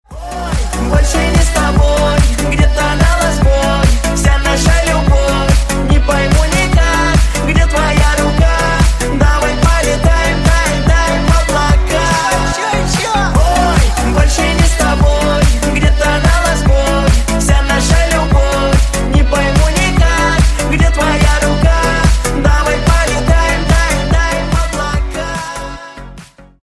Весёлые Рингтоны
Поп Рингтоны